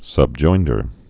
(səb-joindər)